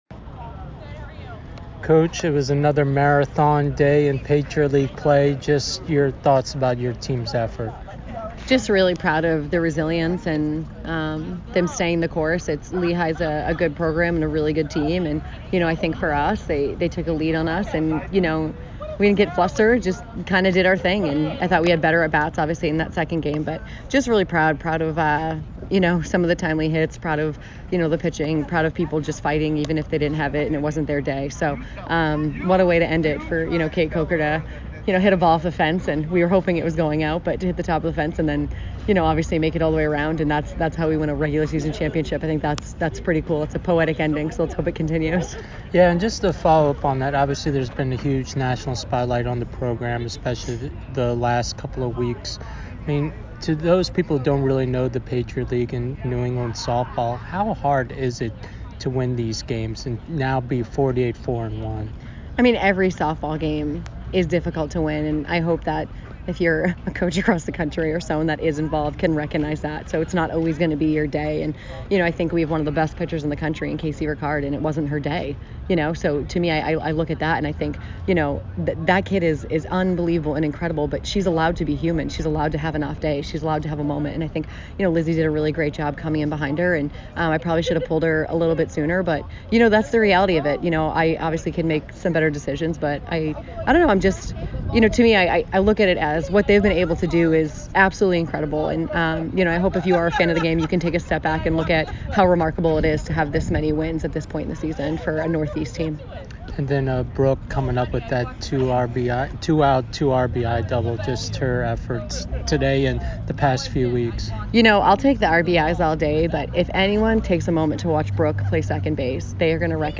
Lehigh DH Postgame Interview